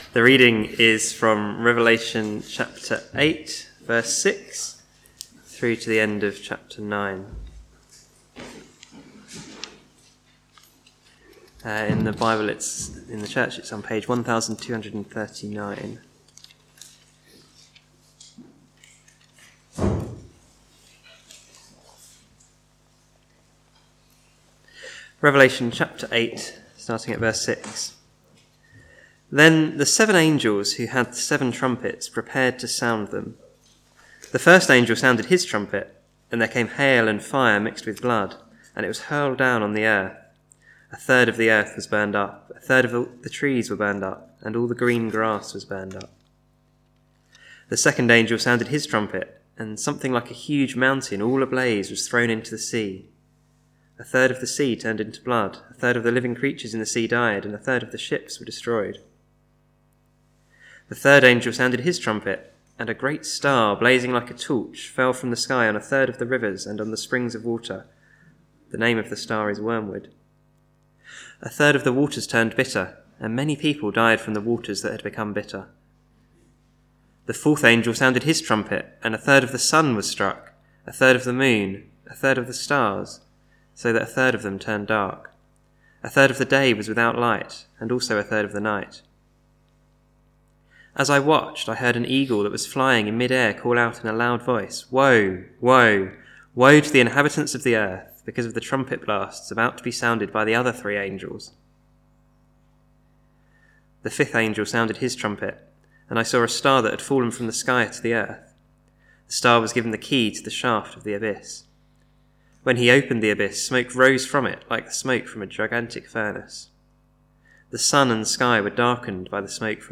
Sermon
Service Evening